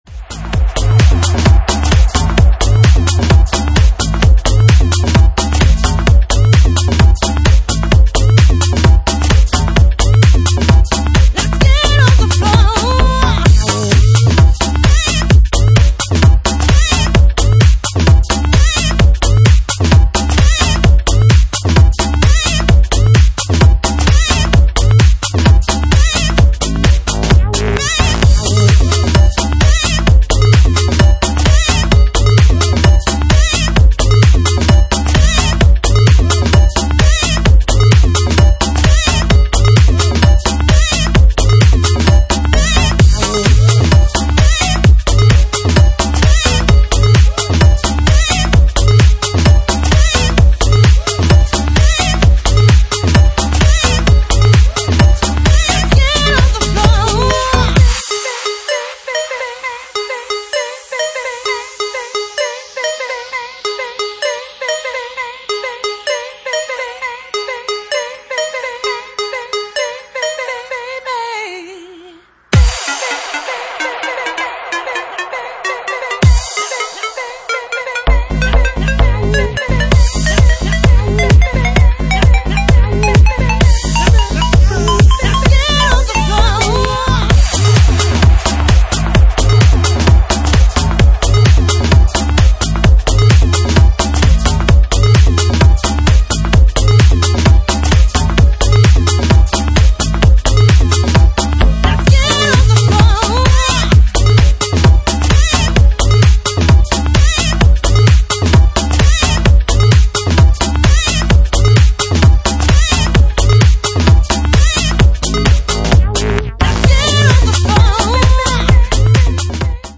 Genre:Jacking House
Jacking House at 65 bpm